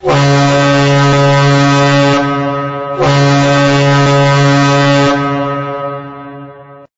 hornNearDual.ogg